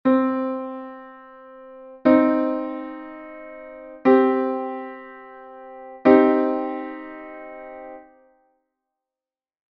- Perfecto Menor: formado por unha 3ª menor e unha 5ª Xusta.
formacion_menor.mp3